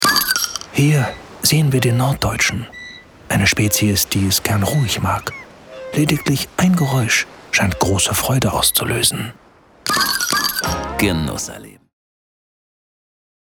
markant, sehr variabel
Mittel plus (35-65)
Commercial (Werbung)